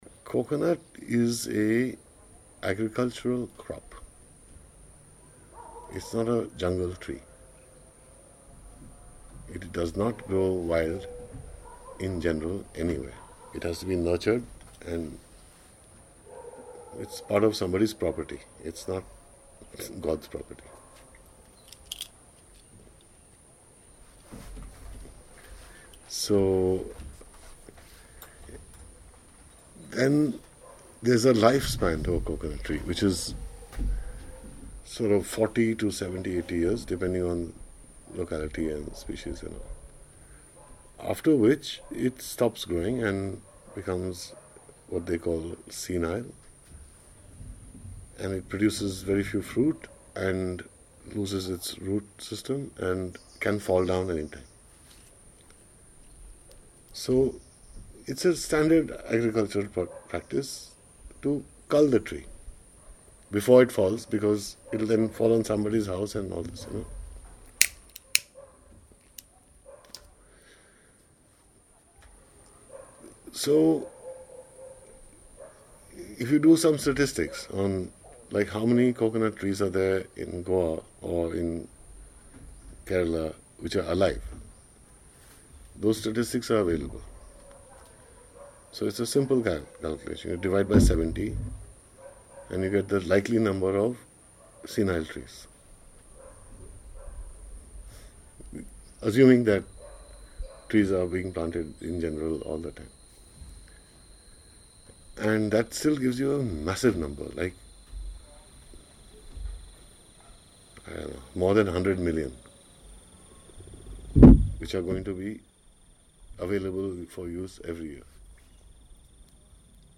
Excerpts from a conversation